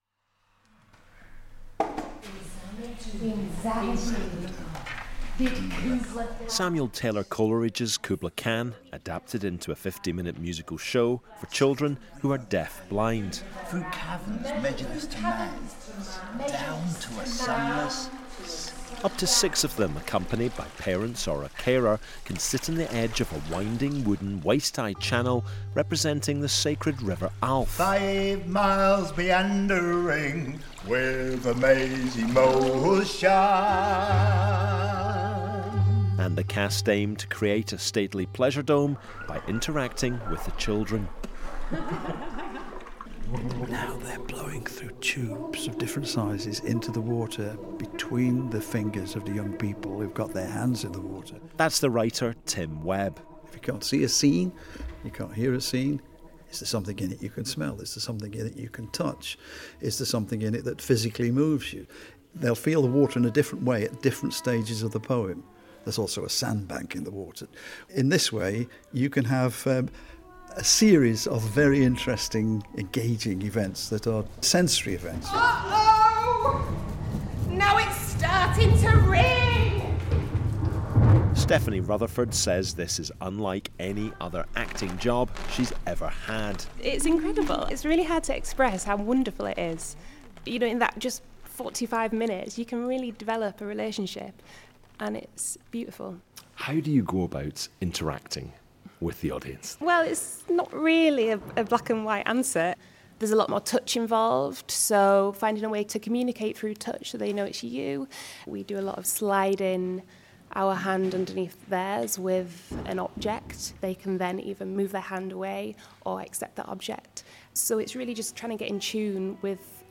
went to a preview for BBC Radio 4's Today programme.